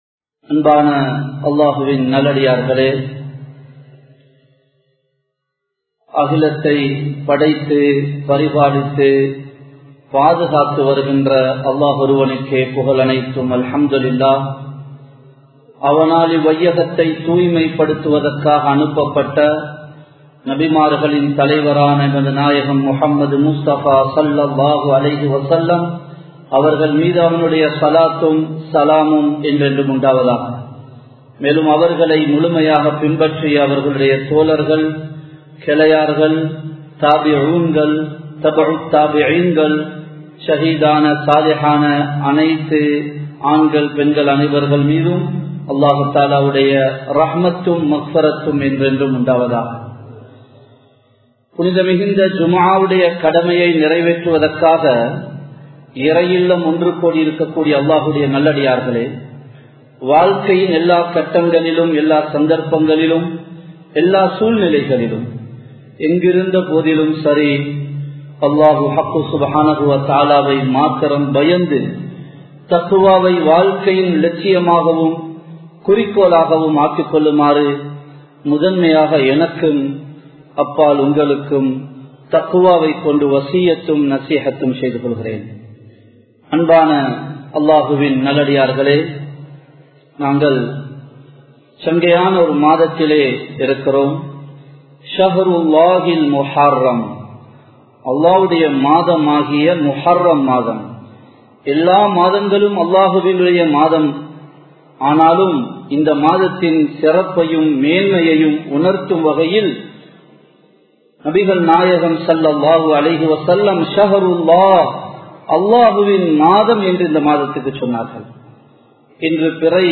Muharram Maathathil 03 Vidayangal (முஹர்ரம் மாதத்தில் 03 விடயங்கள்) | Audio Bayans | All Ceylon Muslim Youth Community | Addalaichenai
Samman Kottu Jumua Masjith (Red Masjith)